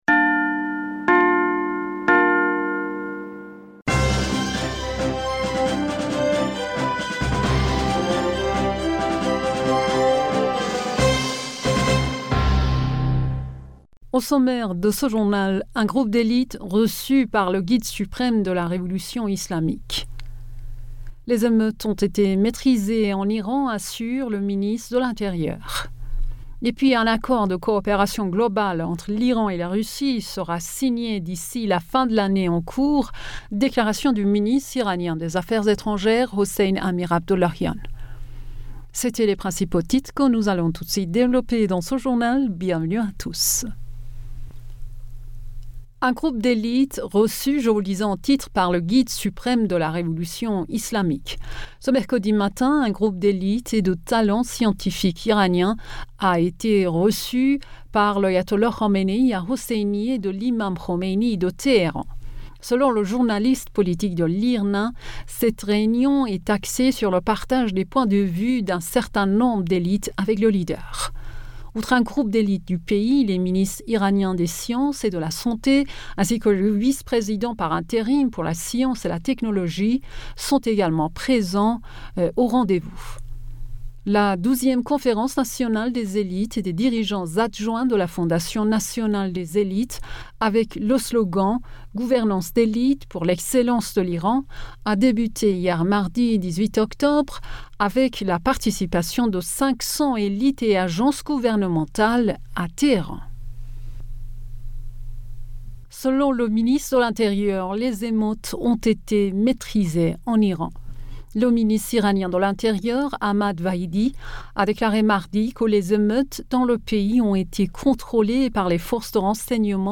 Bulletin d'information Du 19 Octobre